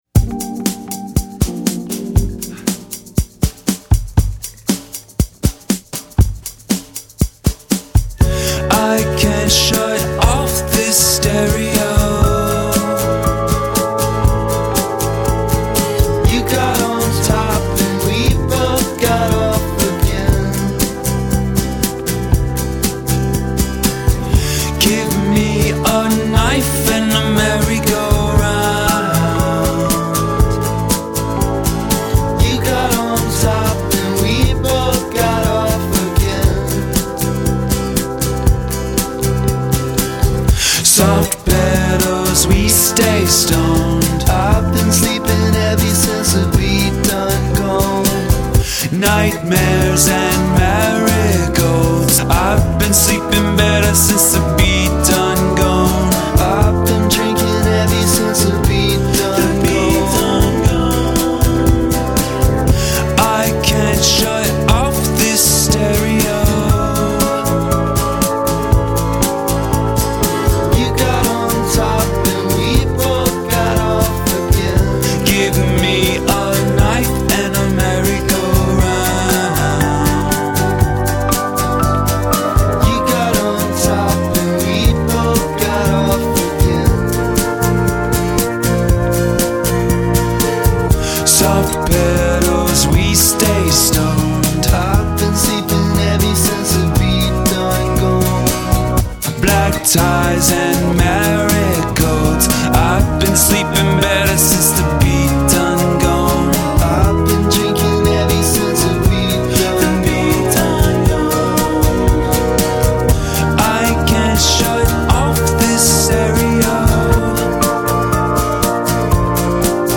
4人組のバンド